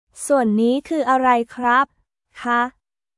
スワン ニー クー アライ クラップ／カ